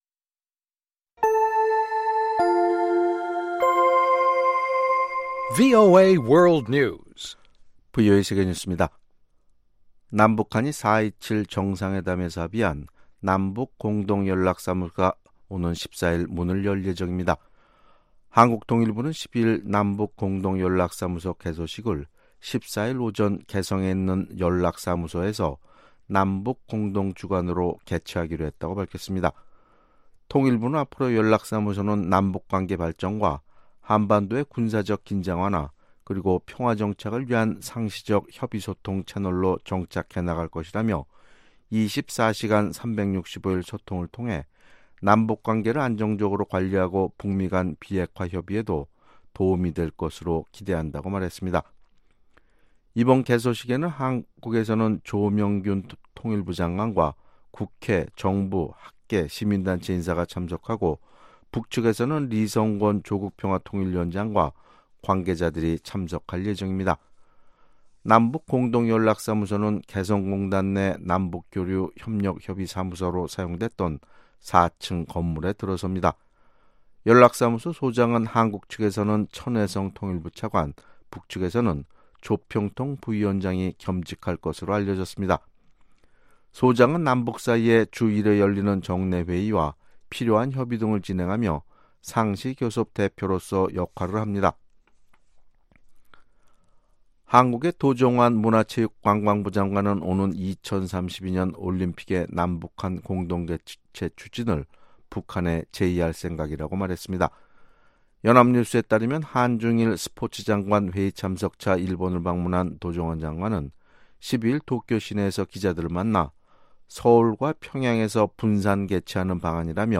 VOA 한국어 아침 뉴스 프로그램 '워싱턴 뉴스 광장' 2018년 9월 13일 방송입니다. 개성의 남북 공동연락사무소가 오는 14일 문을 열고 바로 업무에 들어간다고 한국 통일부가 발표했습니다. 미 국무부는 현재 마이크 폼페오 장관의 방북 계획이나 준비는 없다고 밝혔습니다. 해리스 주한 미국대사는 트럼프 대통령이 비핵화에 대한 김정은 위원장의 진정성을 믿고 있다고 말했습니다.